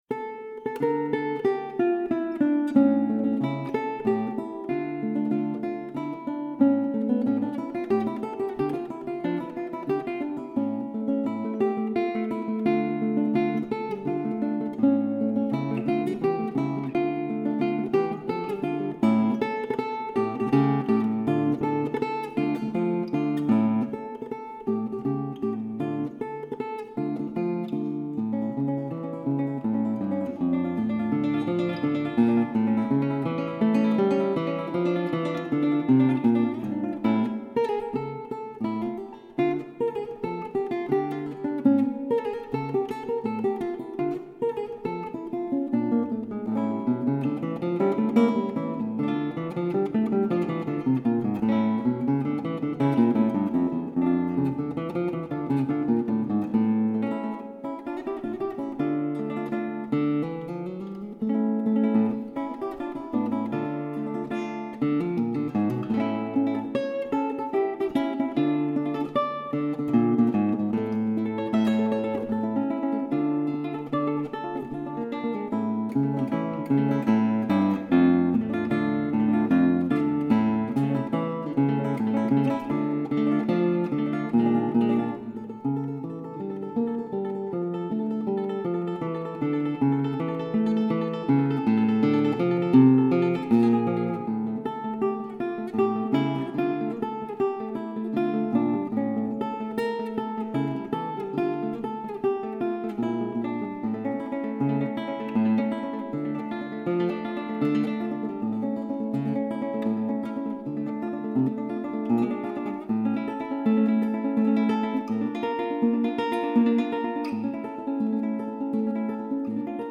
original Fabricatore guitar dating back to 1819 (studio recording